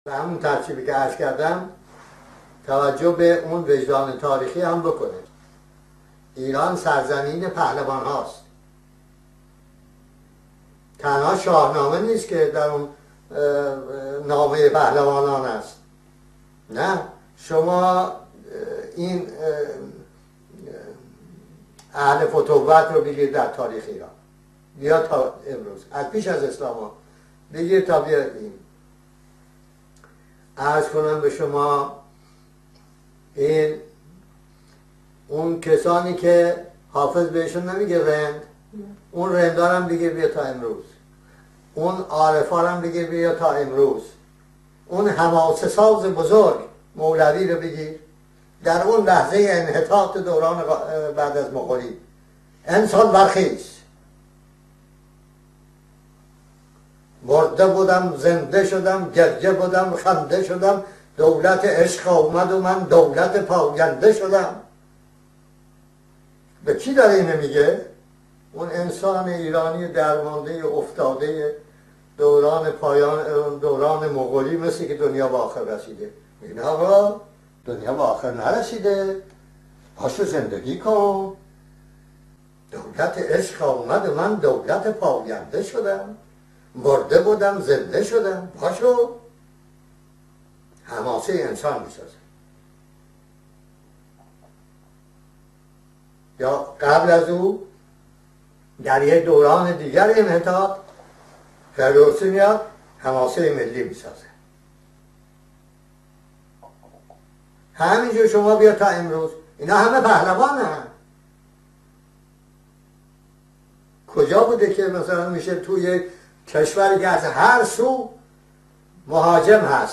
مولانا-خوانی-بنی-صدر-دولت-عشق-آمد-و-من،-دولت-پاینده-شدم-.-و-درخواست-او-از-جوانان.mp3